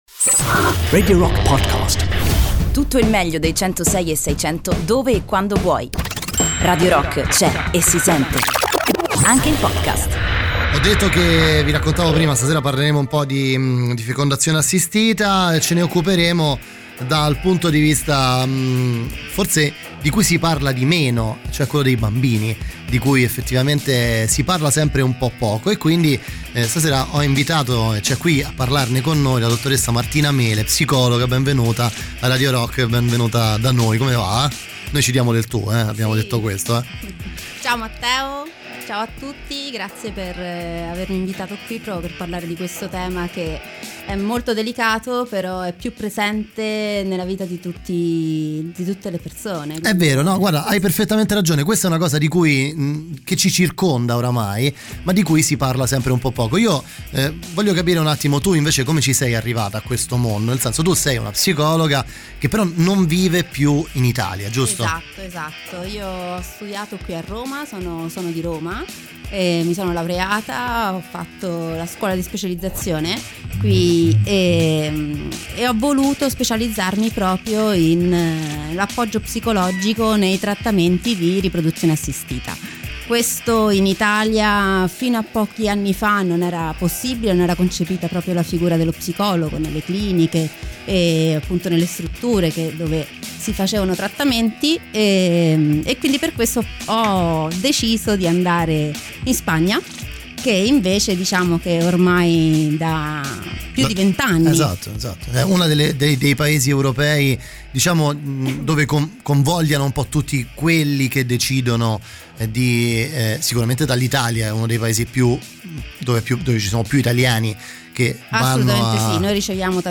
"Intervista"